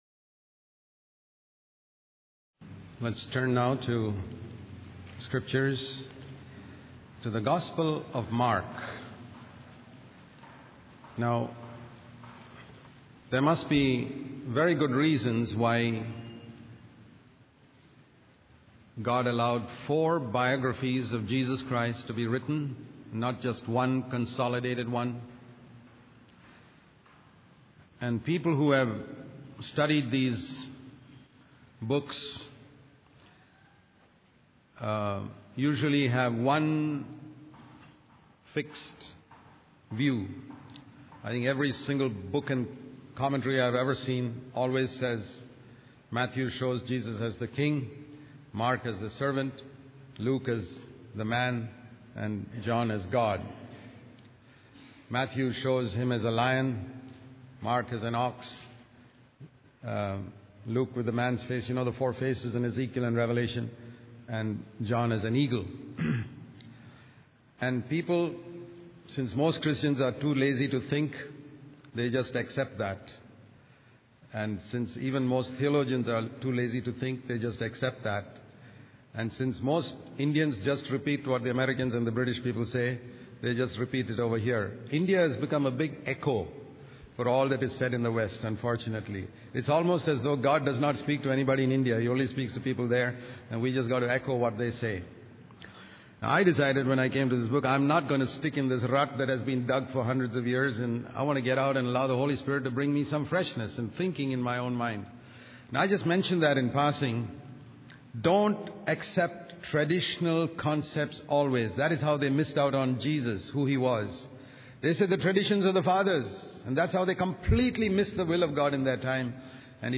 In this sermon, the preacher discusses various passages from the book of Mark in the Bible. He emphasizes the importance of giving cheerfully and sacrificially, using the example of the widow who gave all she had.